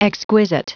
Prononciation du mot exquisite en anglais (fichier audio)
Prononciation du mot : exquisite